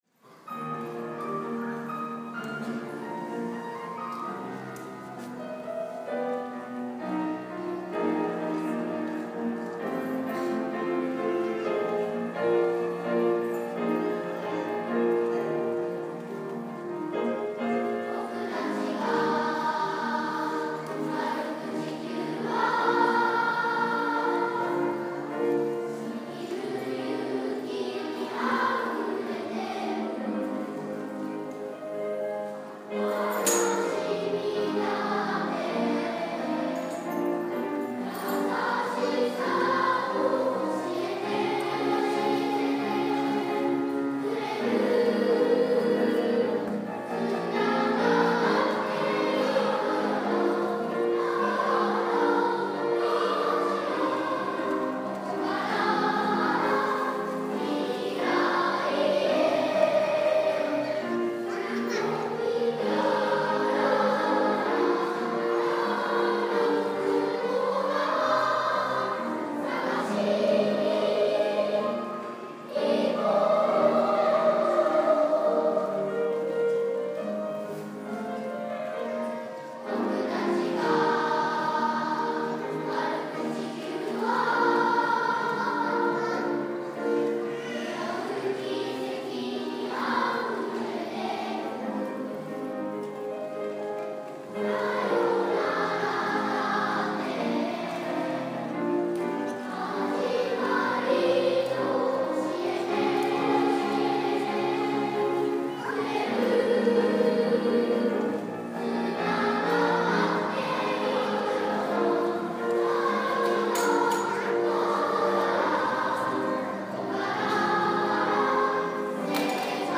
地球を歩け」２部合唱です。
４年生は、中学年のリーダーになり、歌声でも３年生をリードしていきます。
ピアノの伴奏が始まると、緊張していた表情の子たちも笑顔になり、今まで学習でつけた力を発揮して、自分らしく表現をしていきます。会場中を素敵なハーモニーがつつみこみました♪